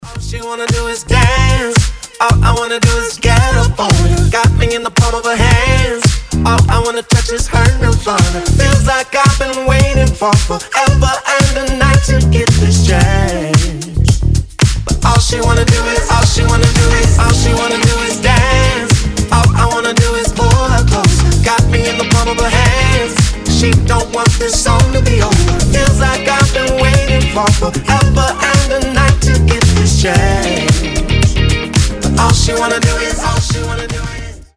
• Качество: 320, Stereo
красивый мужской голос
nu disco
RnB
соул